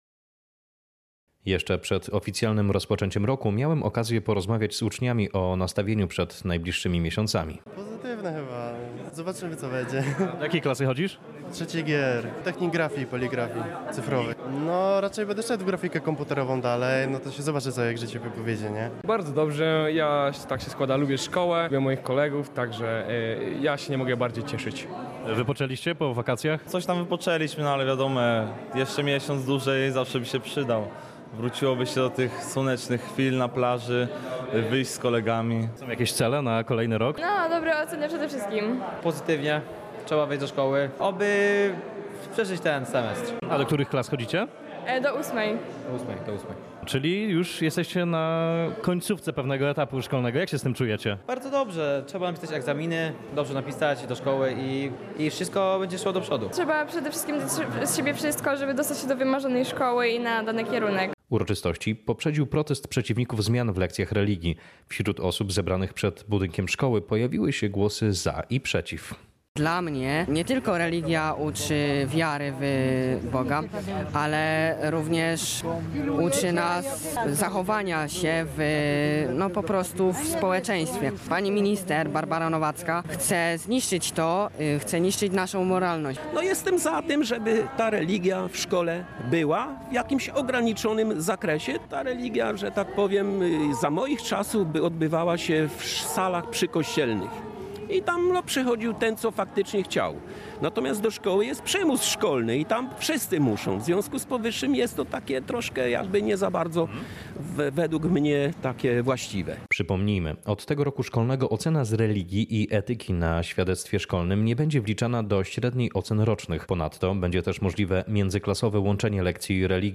W tym roku ogólnopolskie uroczystości odbyły się w Zespole Szkół Technicznych w Mielcu.
Relacja